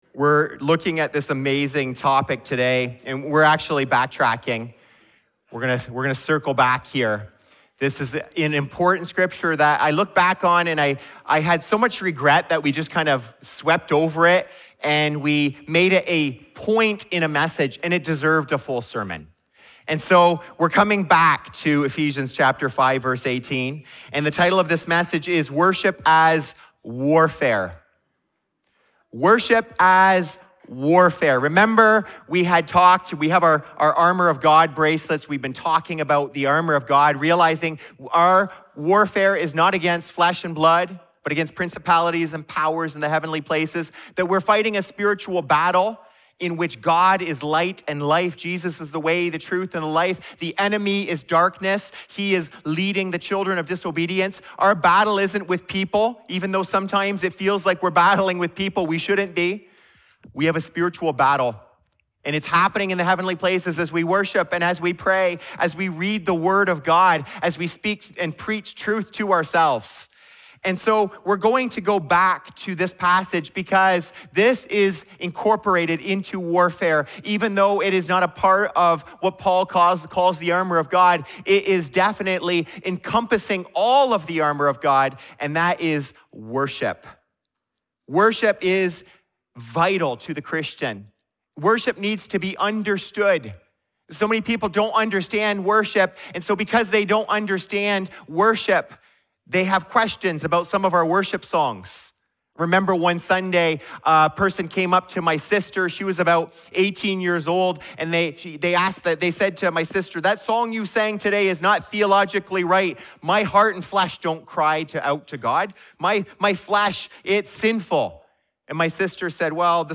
In this sermon, we walk through what it means to approach worship as warfare, under three headings: preparation, participation, and posture. Worship prepares our hearts, builds up the body, and pushes back darkness.